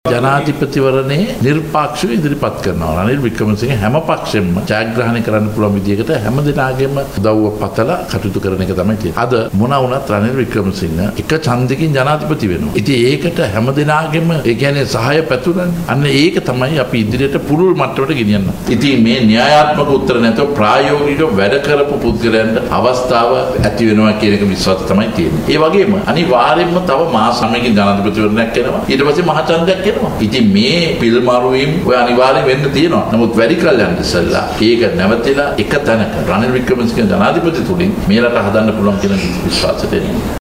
ඔහු මේ බව සදහන් කලේ ඊයේ පැවති මාධ්‍ය හමුවකට එක් වෙමින්.